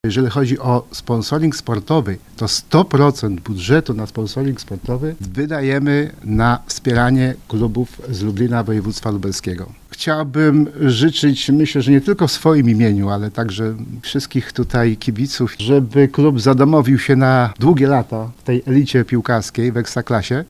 Firma Perła Browary Lubelskie S.A. będzie sponsorem głównym piłkarzy Motoru Lublin. O podpisaniu umowy poinformowali przedstawiciele obu stron na konferencji prasowej.